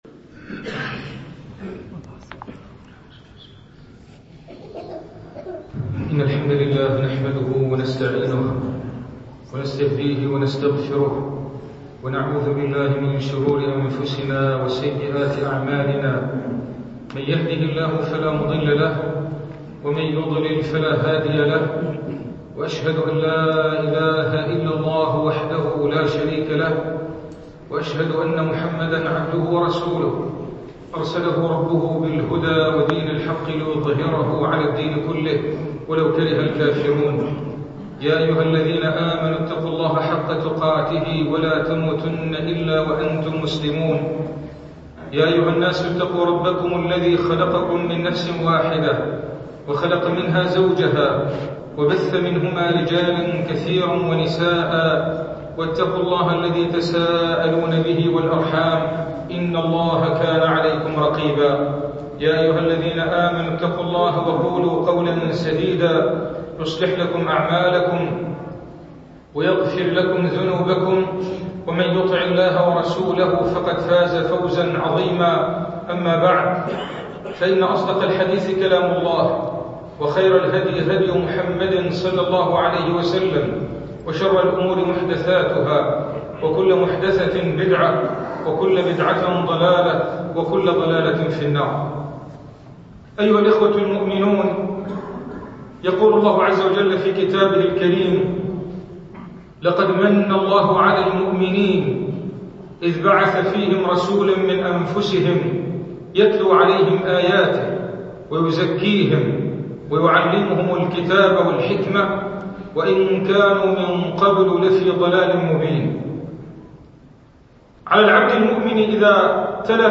[منبر الجمعة]
المكان : المسجد البحري